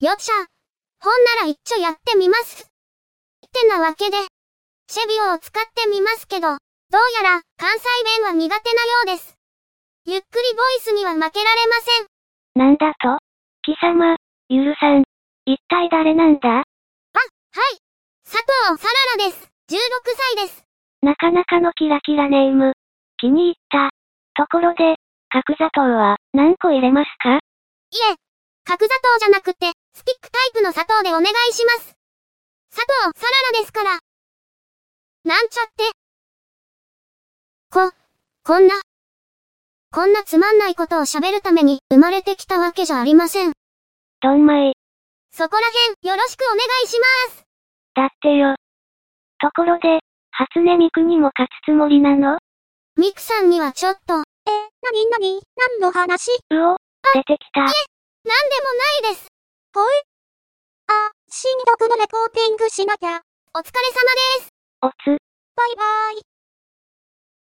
フリーの音声合成ソフトが出たらしいので、落として喋らせてみた。
こんな感じで、かなり自然に喋ってる。凄いな。
色んなパターンを喋らせようとしたら、なぜか謎の掛け合いになってしまった。。。